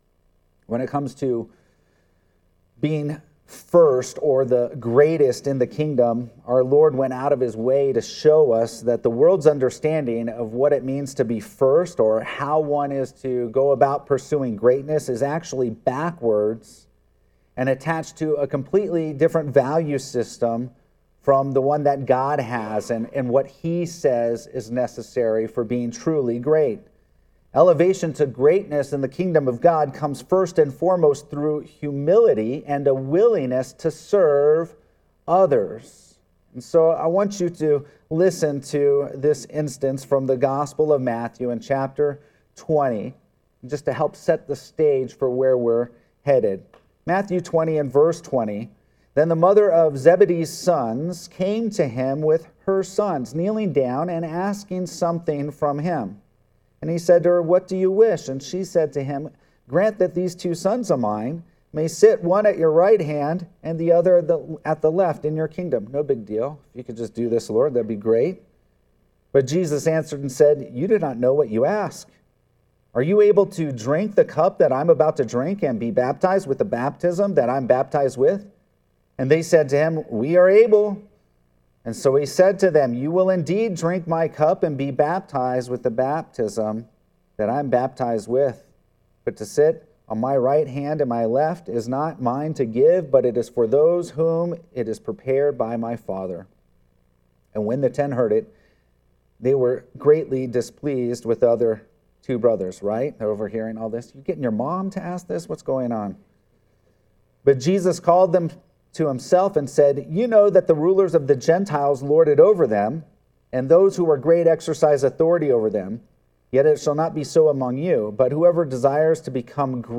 Sermons | Redemption Hill Church